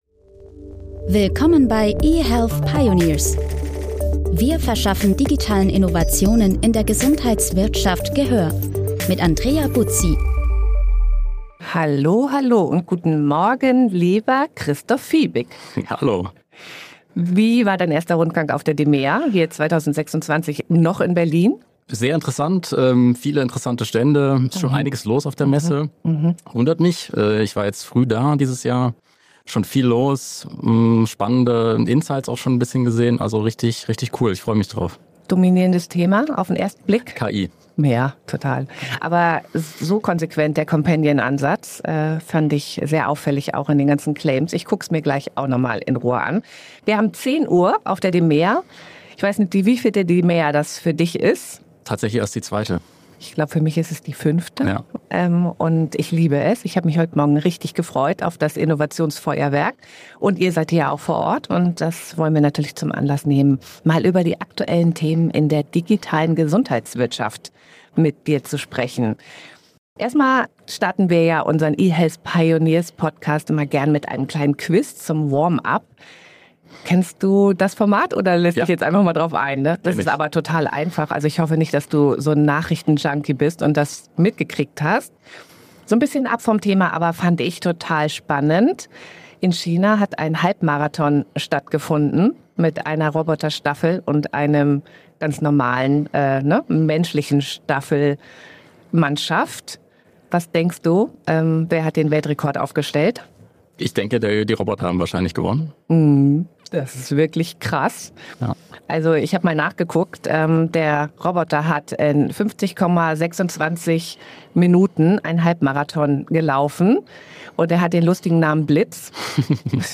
#146: DMEA-LIVE: Plattformökonomie statt Praxis-Chaos